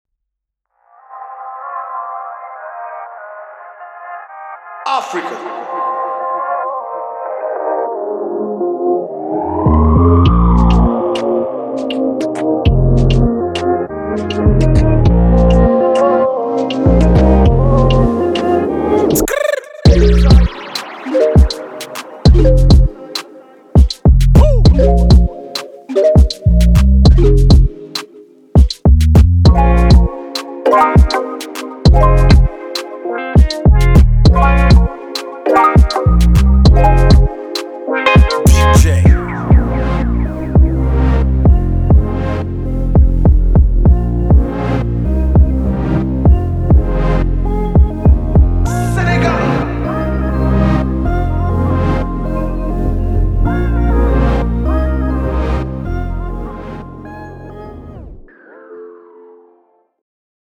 Afropop & Afrobeats
It explores the regional take on Afro Dancehall.